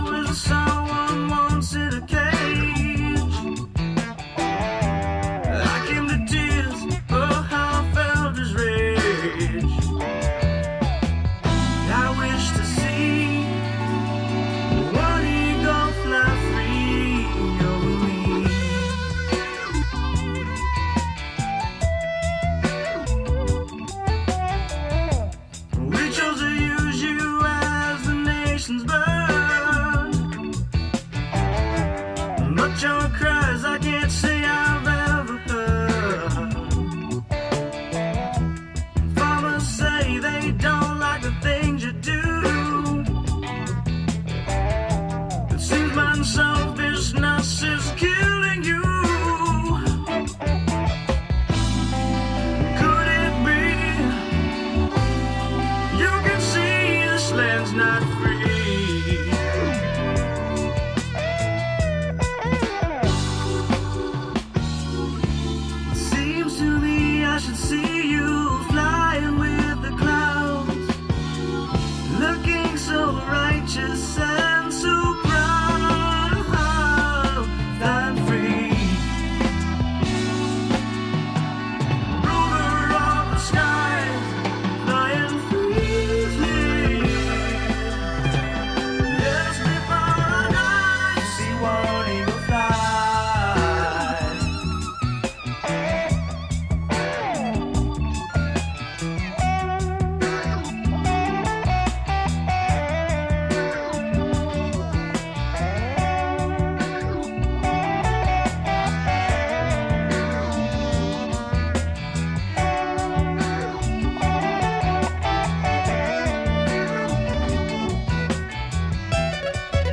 美しいメロディー、ハーモニーに思わず聴き入ってしまう傑作揃いです！